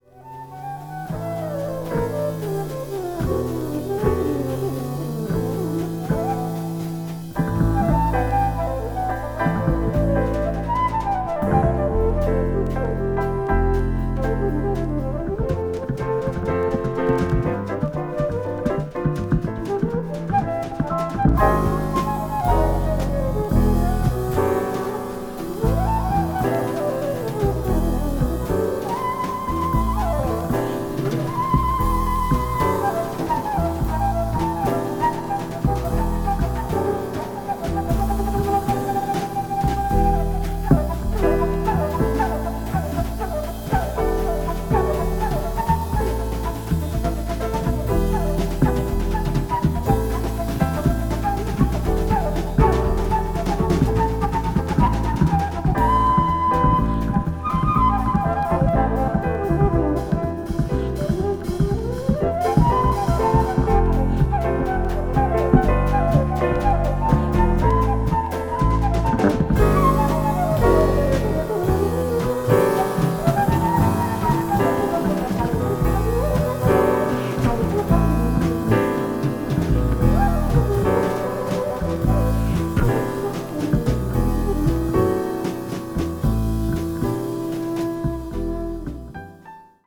contemporary jazz   deep jazz   post bop   spiritual jazz